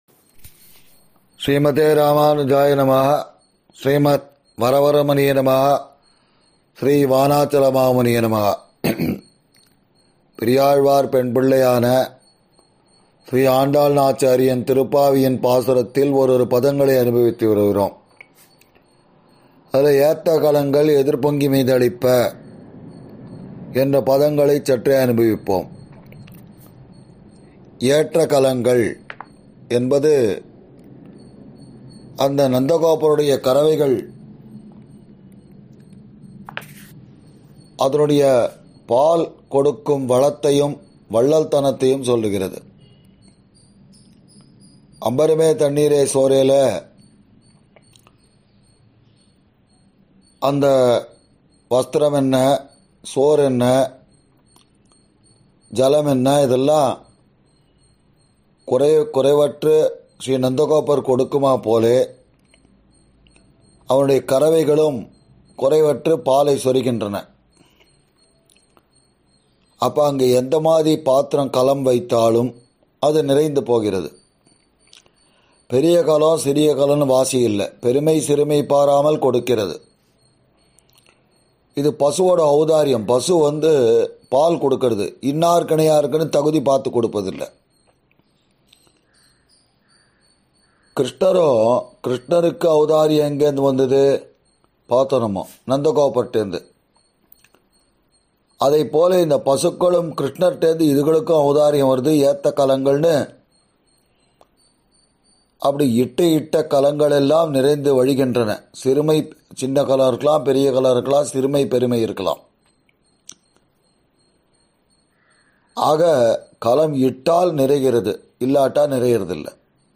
சார்வரி ௵ மார்கழி ௴ மஹோத்ஸவ உபன்யாசம் –